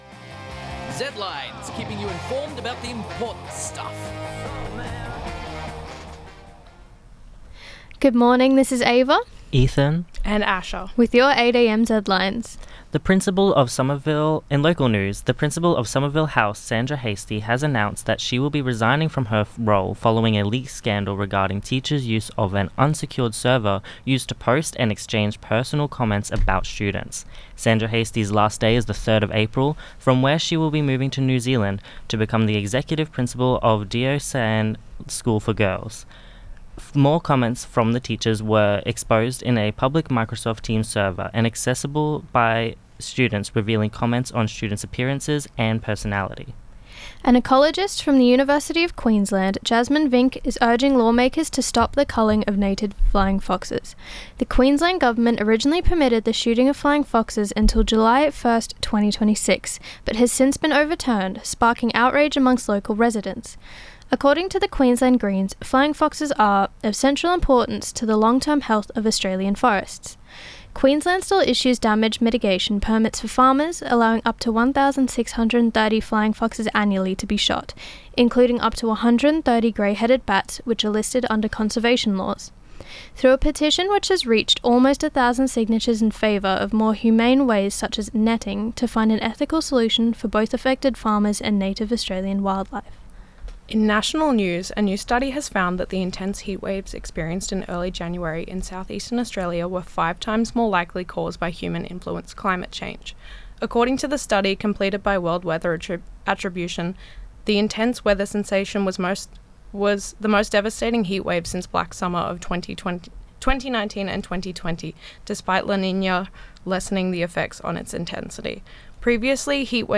Zedlines Bulletin 8am_1.mp3 (9.24 MB)